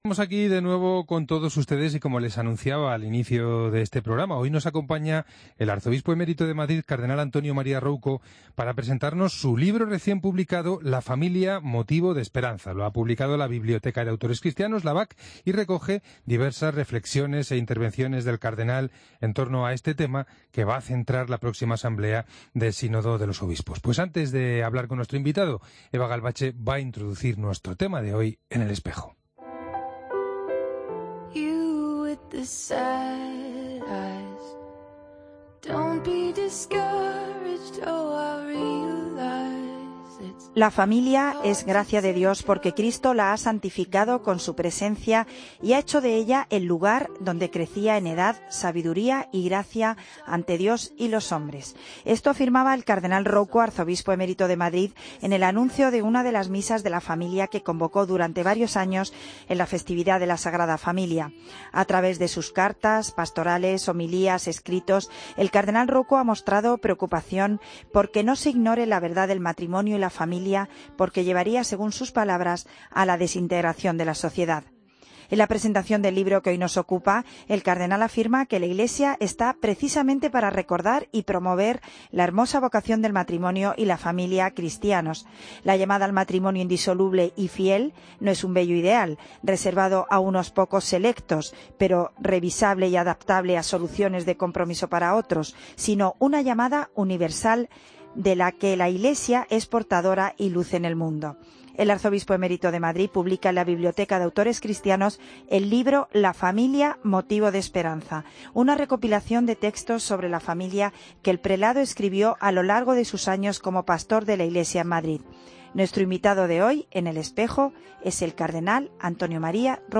Escucha la entrevista al cardenal Antonio María Rouco Varela en el Espejo